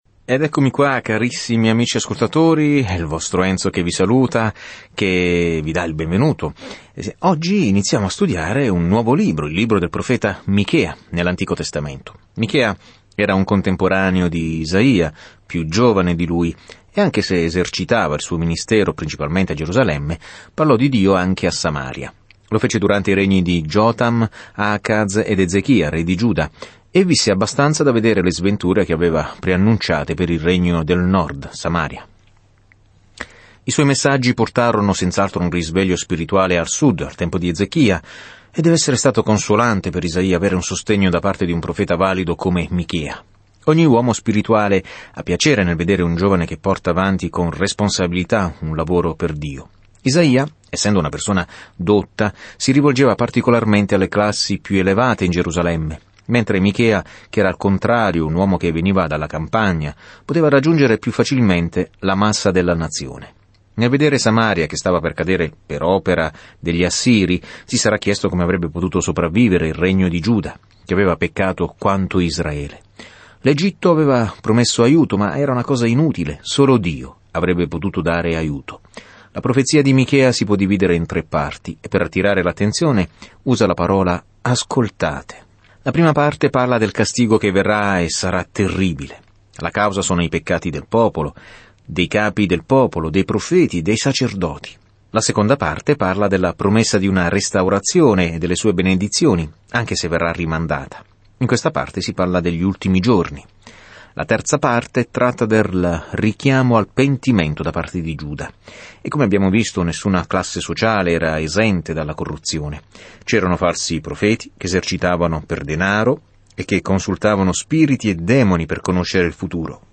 Scrittura Michea 1:7 Inizia questo Piano Giorno 2 Riguardo questo Piano In una bella prosa, Michea invita i leader di Israele e Giuda ad amare la misericordia, ad agire giustamente e a camminare umilmente con Dio. Viaggia ogni giorno attraverso Michea mentre ascolti lo studio audio e leggi versetti selezionati della parola di Dio.